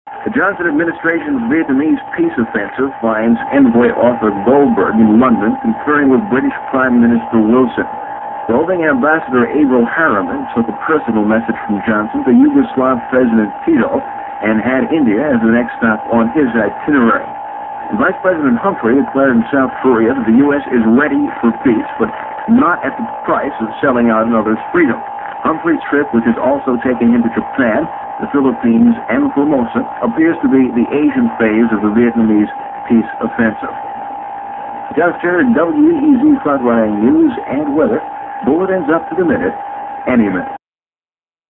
While the quality is not up to broadcast standards, it is the only copy available.